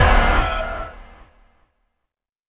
HIT 10.wav